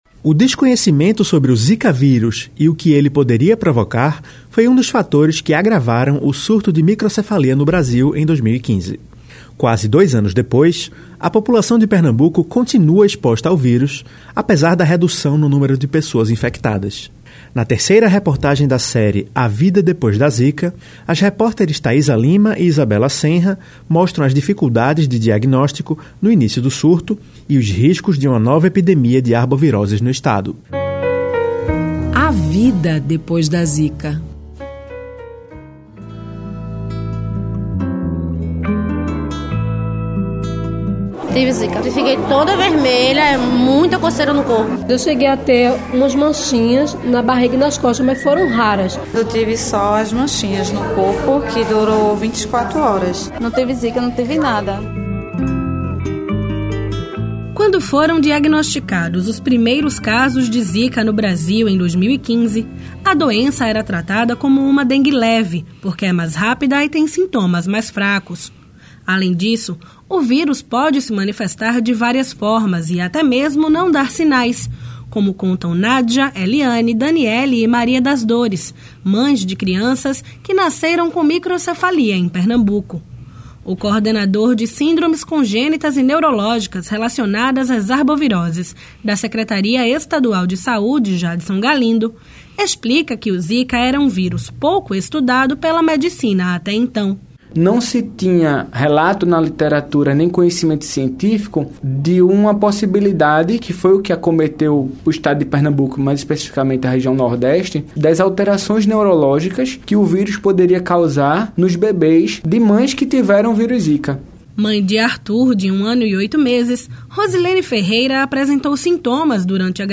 Em julho e agosto de 2017, a Rádio Alepe publicou uma série de reportagens sobre os desafios enfrentados pelas famílias de bebês diagnosticados com microcefalia provocada pelo zika vírus e a situação da epidemia quase dois anos depois que os primeiros casos apareceram, em agosto de 2015.